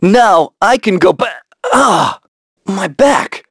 Evan-Vox_Victory_b.wav